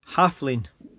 [HAF-leen]